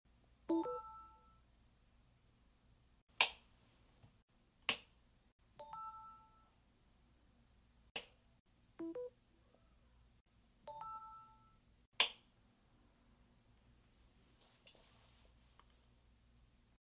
S25 One UI 7 vs S24 One UI 6 Sounds Charging